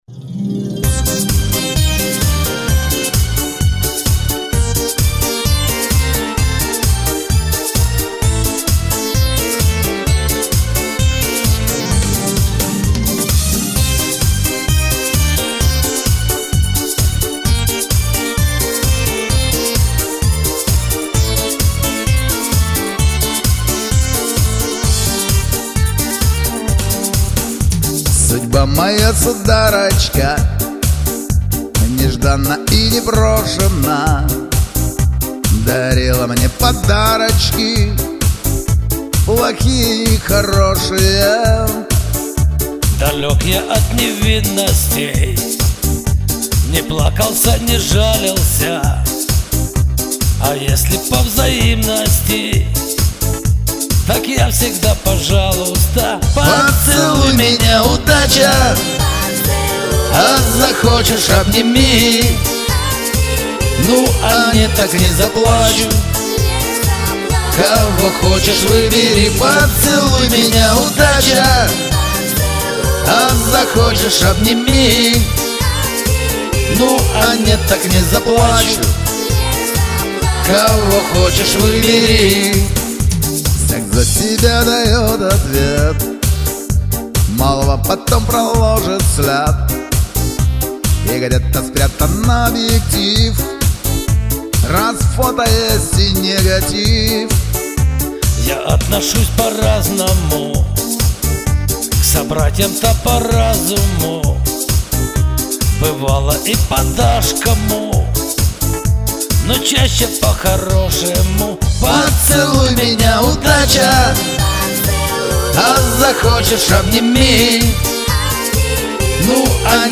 Песенка заводная ))))555